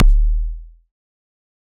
EDM Kick 49.wav